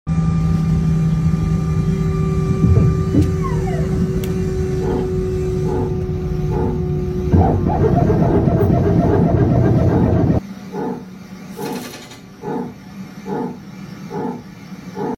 Warming The Engine ✈ Sound Effects Free Download